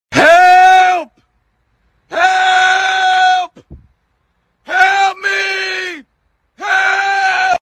Meme Effect Sound